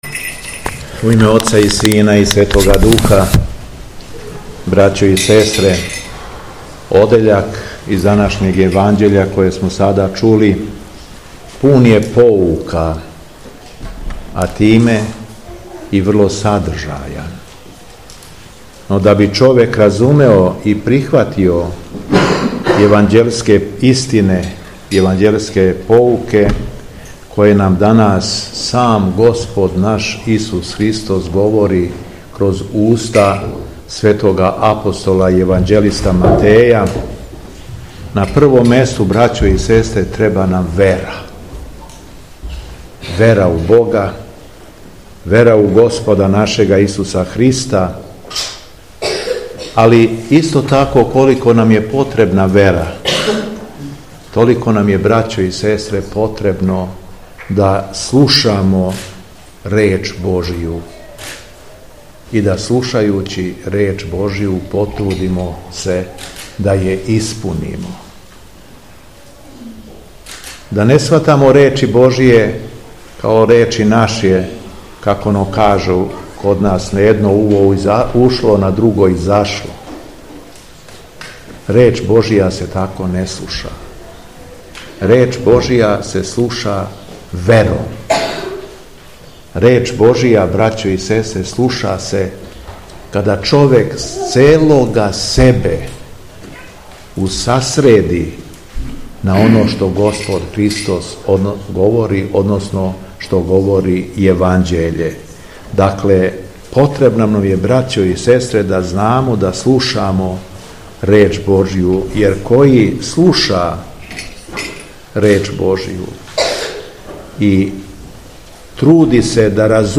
Беседа Његовог Високопреосвештенства Митрополита шумадијског г. Јована
Након прочитаног зачала из Светог Јеванђеља верном народу се обратио Високопреосвећени рекавши: